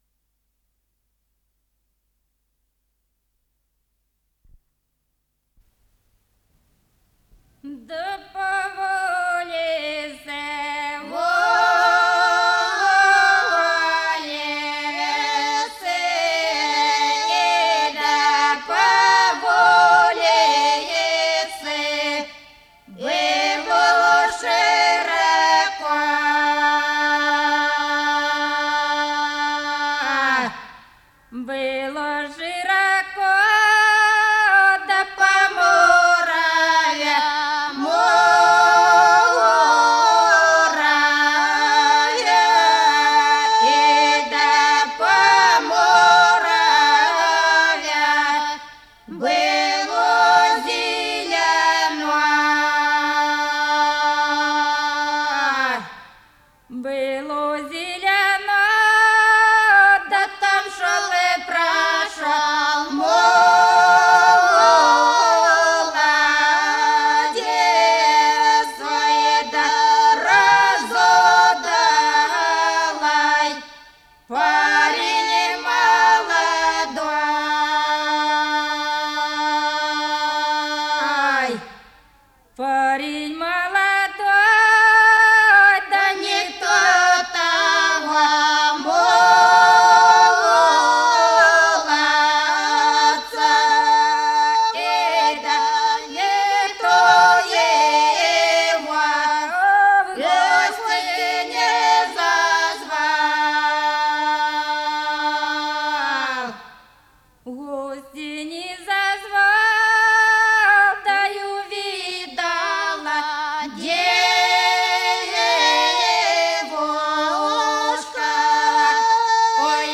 с профессиональной магнитной ленты
Подзаголовок"Лирическая"
КомпозиторыРусская народная песня
ИсполнителиАнсамбль певцов села Плёхово Суджанского района Курской области
ВариантМоно